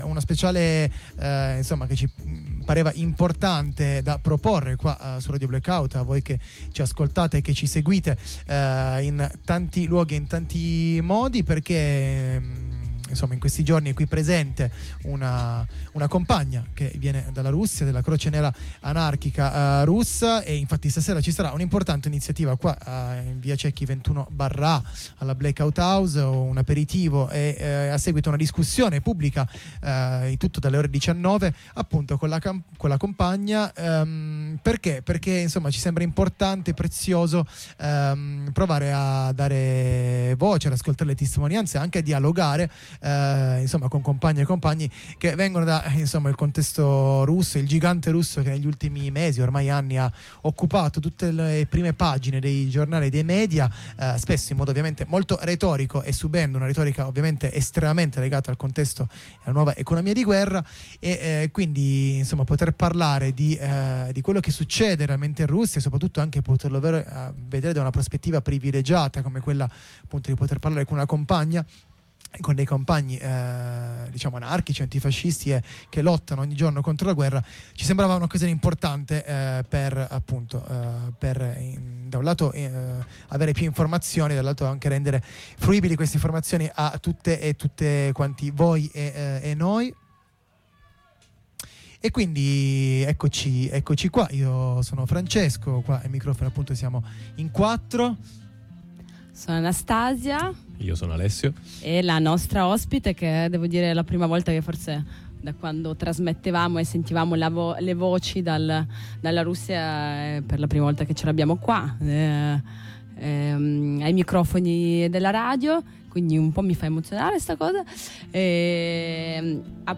Radio Blackout propone un’aggiornamento sulla situazione interna in Russia, con un focus sul dissenso rispetto alla guerra portato da tutte quelle voci antiautoritarie e antifasciste (ma non solo) che il governo Putin ha deciso di reprimere e cancellare prontamente. Lo facciamo grazie alla disponibilità di una compagna della Croce Nera Anarchica di Mosca che ci ha fatto visita negli studios di RBO.
Qui di seguito, l’intervista ai nostri microfoni!